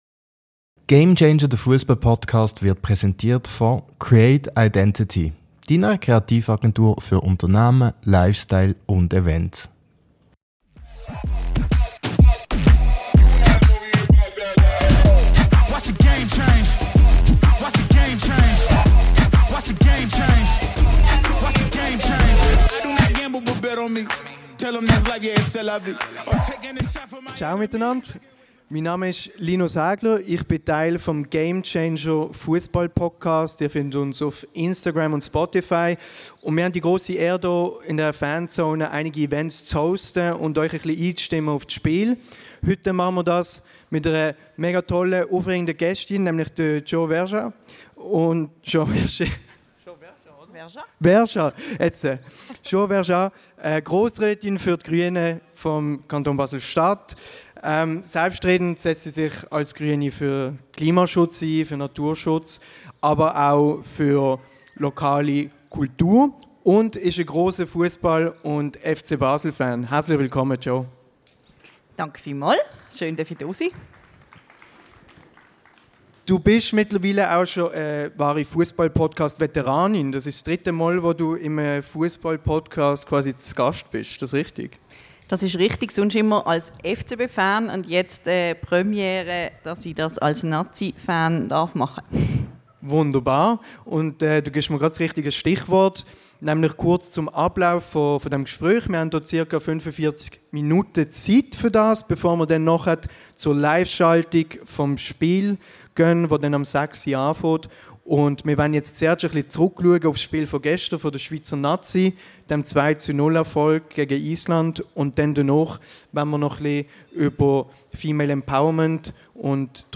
In dieser Spezialfolge durften wir Jo Vergeat, Grossrätin der Grünen Basel-Stadt, auf der Bühne am Barfüsserplatz begrüssen.